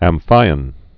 (ăm-fīən)